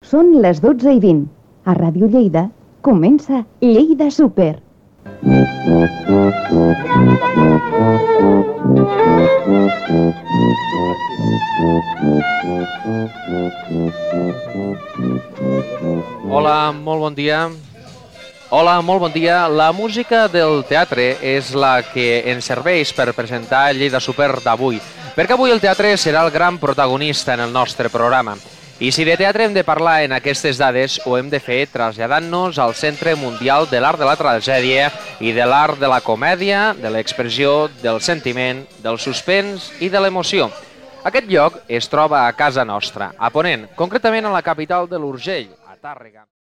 Hora, identificació, presentació de l'espai dedicat al teatre fet des de Tàrrega
Info-entreteniment